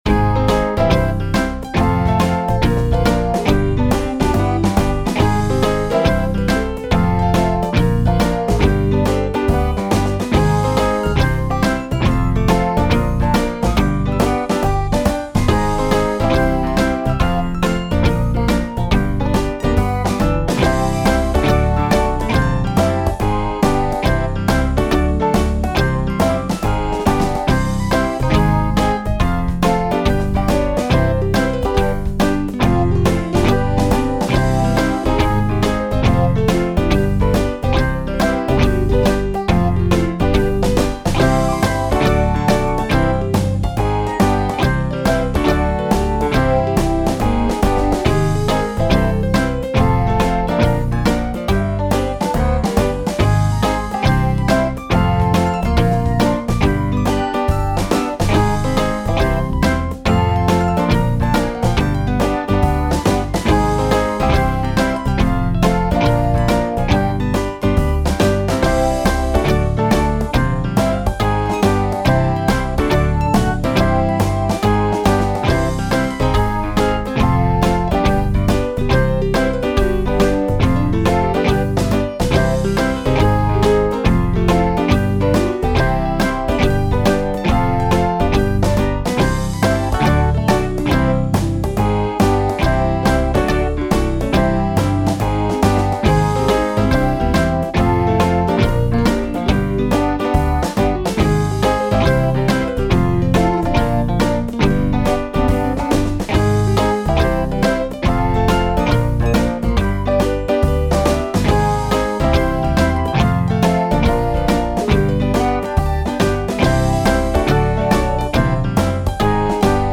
midi-demo 2 midi-demo 3